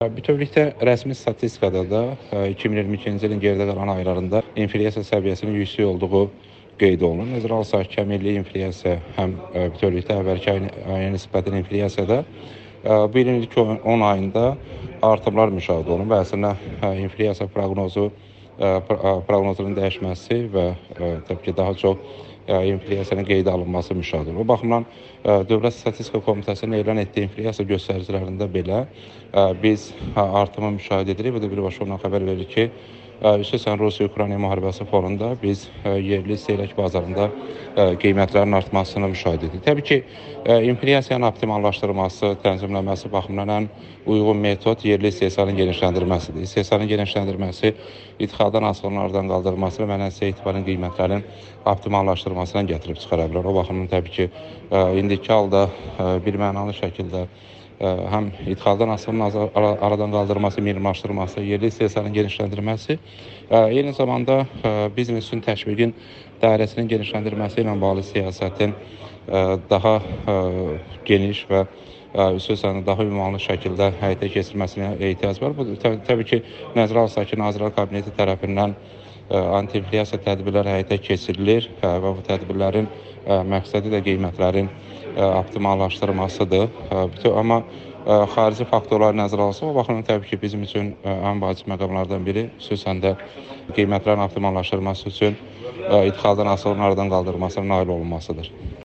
Milli Məclisin deputatı Vüqar Bayramov Amerikanın Səsinə müsahibəsində bildirib ki, rəsmi statistikada 2022-ci ilin geridə qalan aylarında inflyasiya səviyyəsinin yüksək olduğu qeyd olunur.